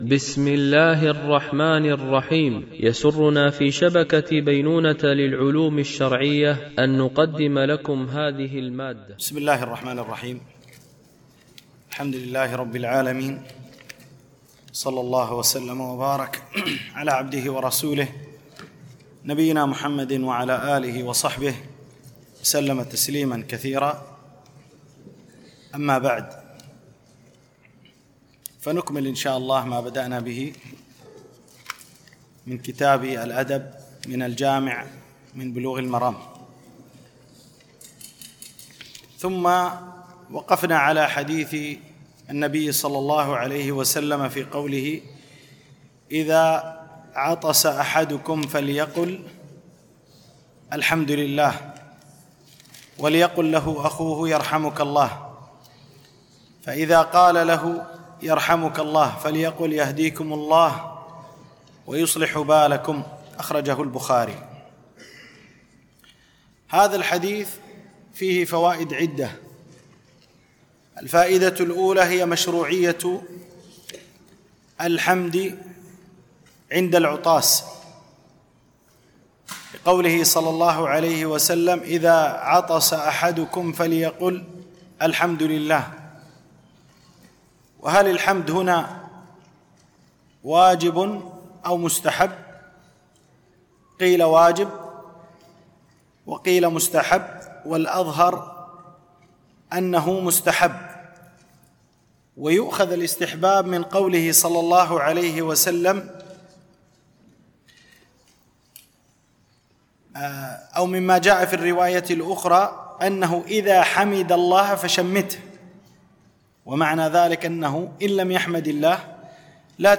شرح باب الأدب من كتاب الجامع من بلوغ المرام ـ الدرس 03
دورة علمية مترجمة للغة الإنجليزية، لمجموعة من المشايخ، بمسجد أم المؤمنين عائشة رضي الله عنها